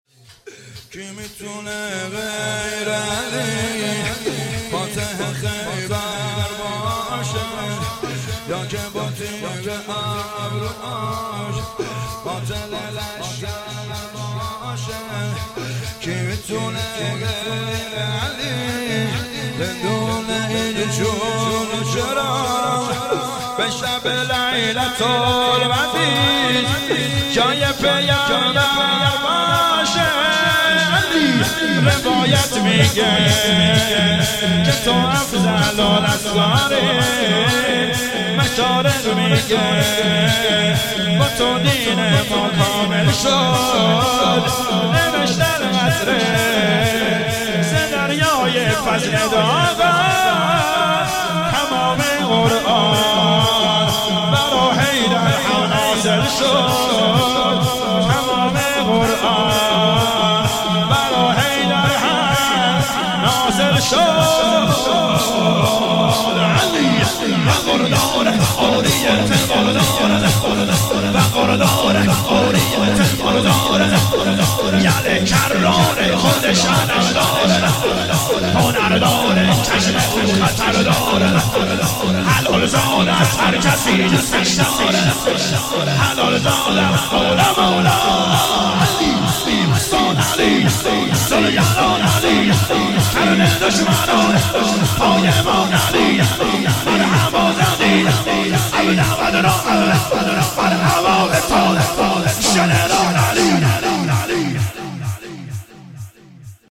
متن شور امام علی